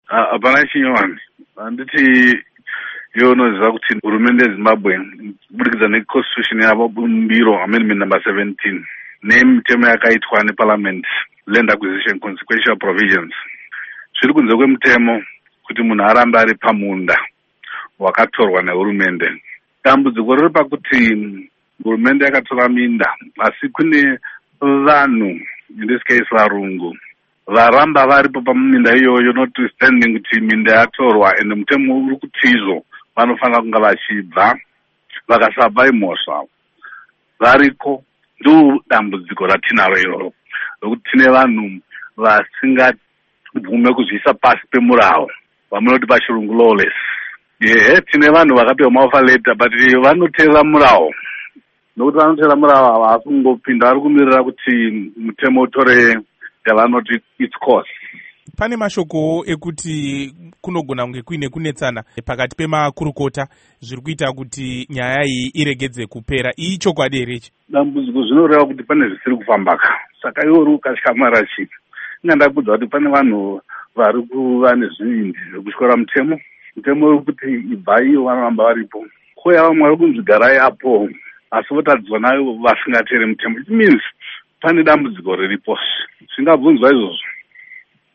Hurukuro naVaJohannes Tomana